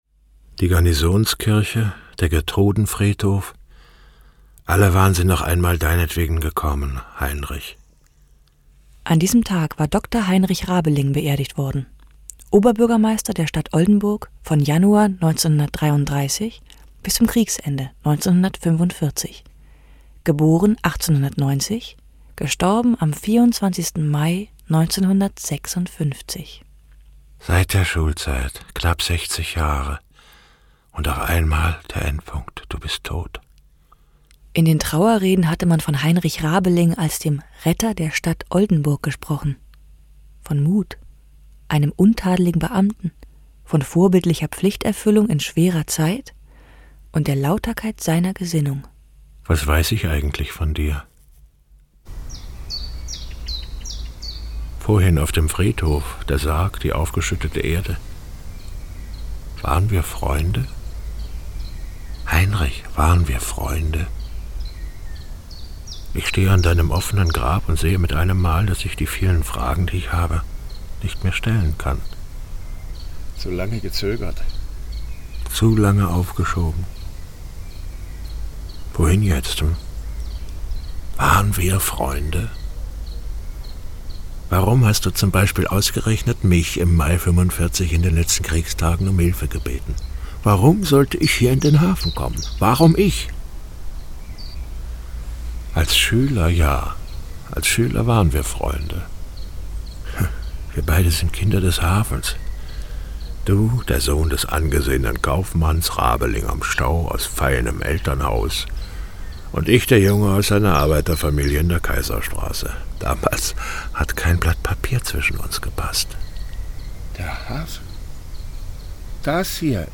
Über Kopfhörer werden einfach verschiedene ‘akustische Folien’ über den sichtbaren Alltag gelegt.
Hören Sie die folgenden Ausschnitte bitte am besten über Kopfhörer. Nur so bekommen Sie einen unverfälschten Eindruck vom dreidimensionalen Klang bestimmter Aufnahmen.
In Ausschnitt 6 aus dem Hörgang Hafen begegnet man direkt an der Kaimauer einem Mann, der sich an seinen ehemaligen Freund erinnert und die dramatischen Stunden, die sie beide im Mai 1945 an genau dieser Stelle erlebt haben.